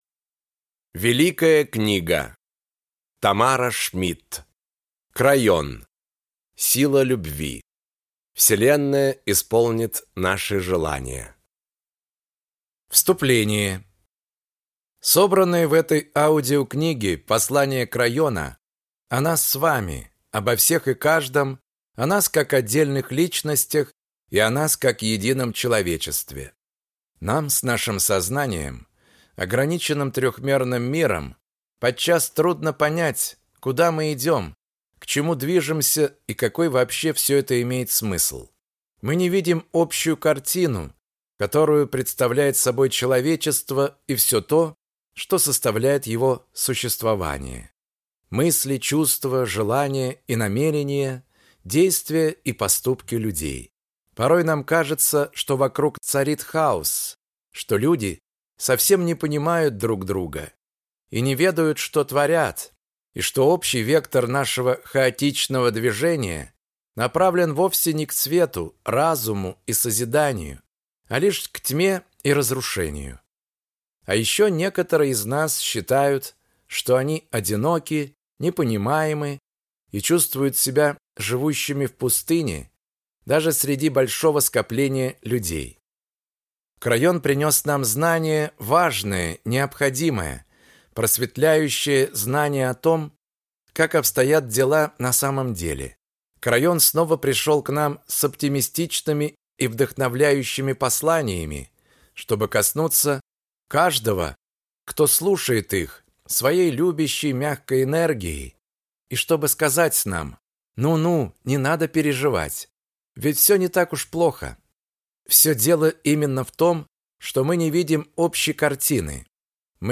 Аудиокнига Крайон. Сила Любви. Вселенная исполнит наши желания | Библиотека аудиокниг